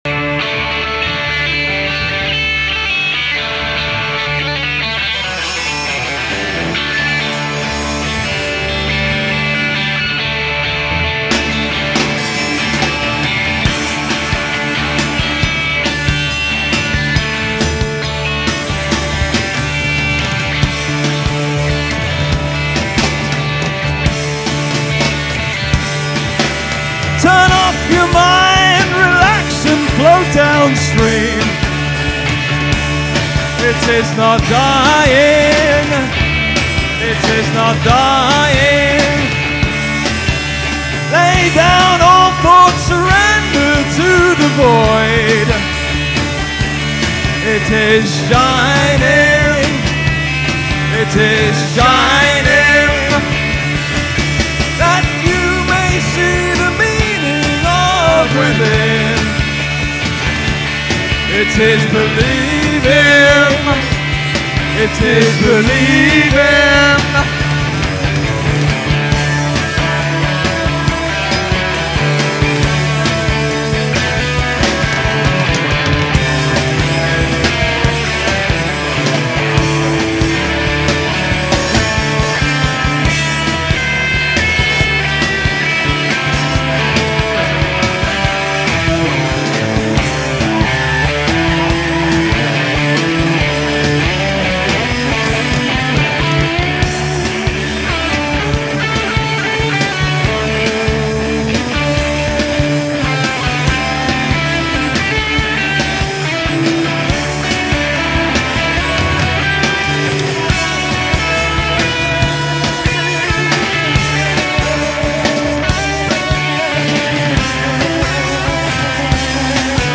recorded live
guitar, vocal
bass
Highlights of three full-length (45min) gigs.
on harmonica, Totnes Civic Hall, 15 November 1991: